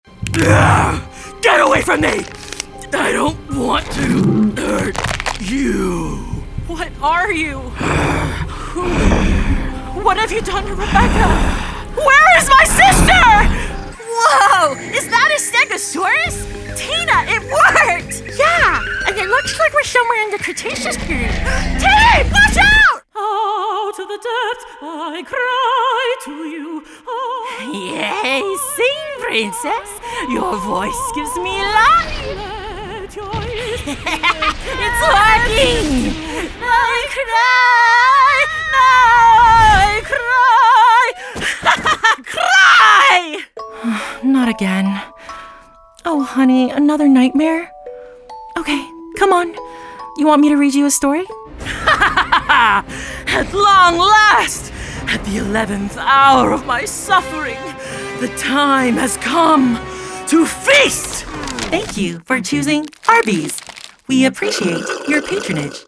Voiceover Demos
Animation Demo